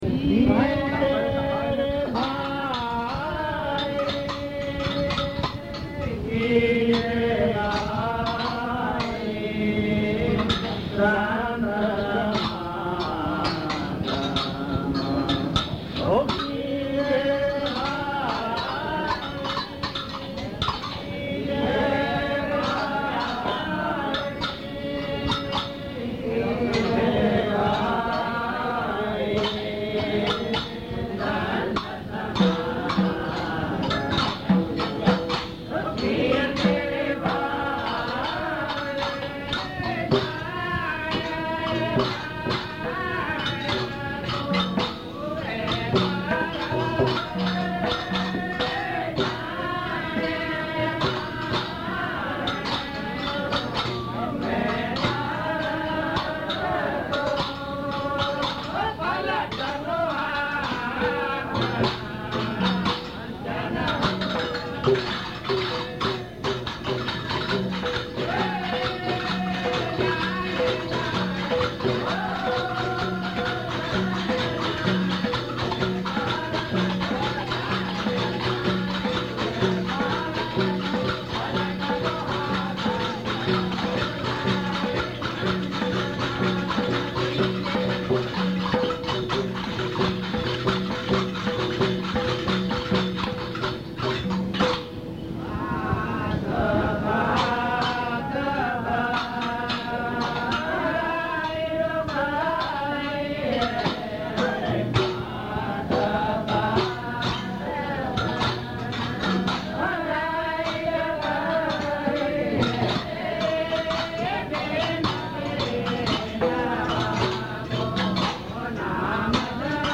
સંત સમાગમ કીજે રે ભાઈ (નાદબ્રહ્મ પદ - ૧૫૬, રાગ - કલ્યાણ)Sant Samā-gam ...